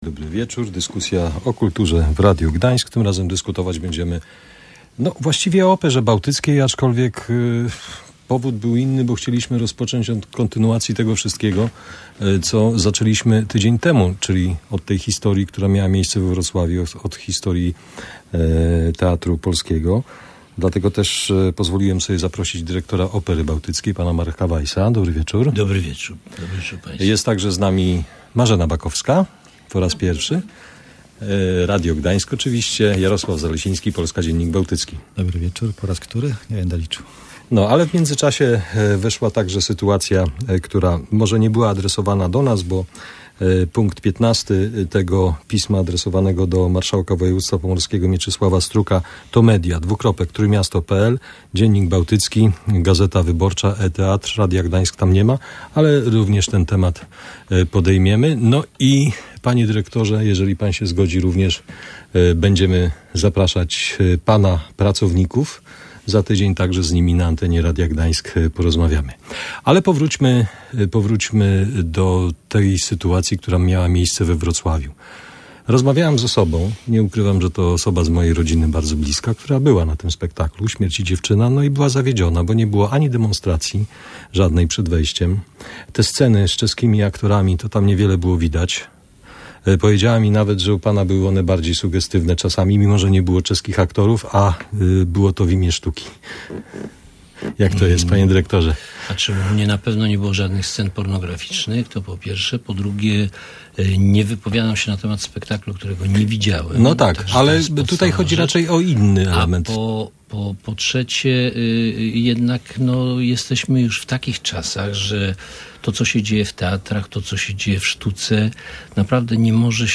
Przyznał to na antenie Radia Gdańsk w czasie poniedziałkowej Debaty o Kulturze, która była poświęcona sytuacji w instytucji.